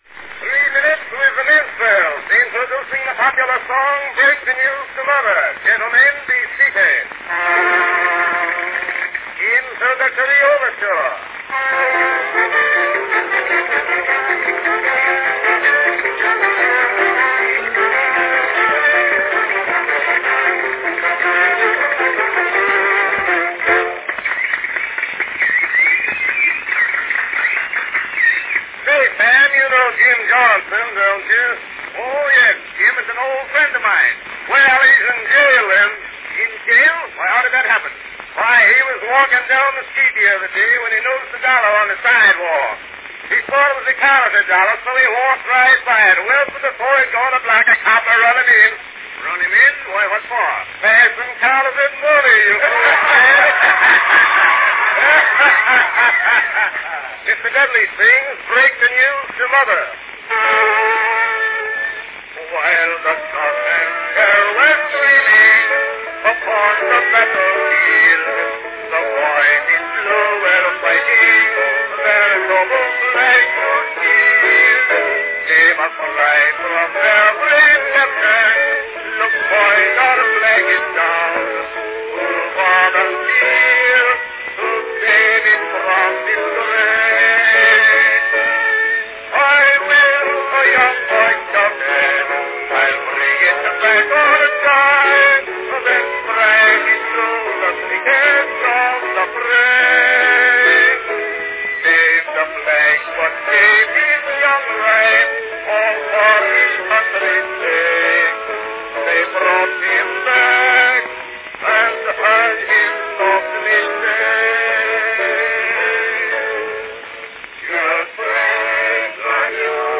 Category Minstrels
Performed by S. H. Dudley & Arthur Collins
Announcement "Three Minutes With the Minstrels, introducing the popular song 'Break the News to Mother'. Gentlemen, be seated."
Part of Edison's popular Minstrel series of recordings, here we experience a highly compressed minstrel show on a brown wax cylinder.   The featured song Break the News to Mother was a hit song from 1897 about casualties of war.